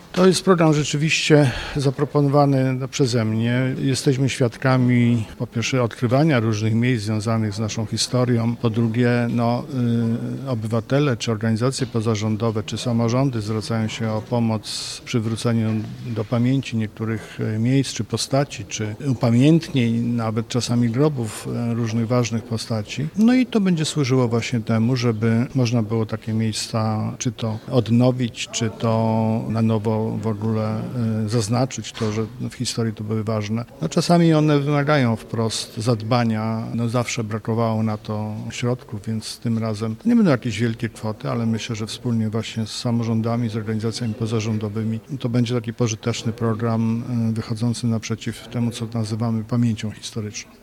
Mówi marszałek województwa Adam Struzik.